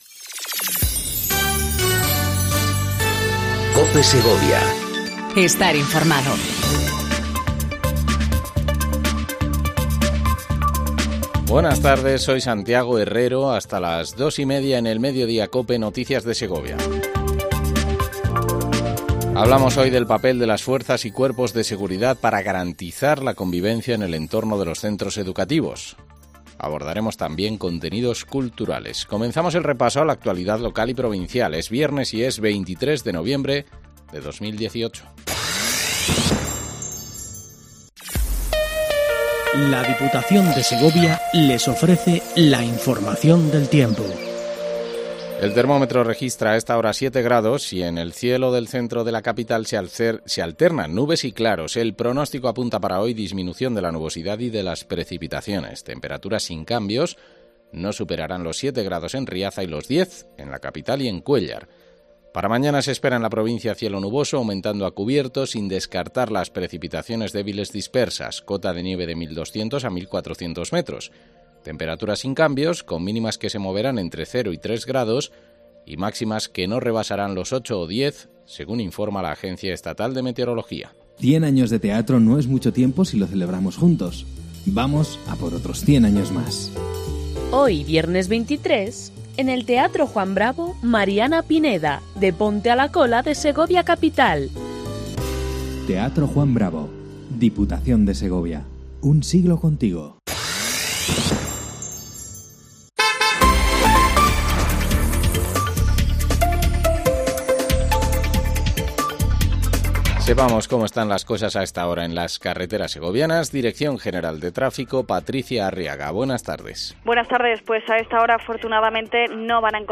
INFORMATIVO MEDIODÍA EN COPE SEGOVIA 14:20 DEL 23/11/18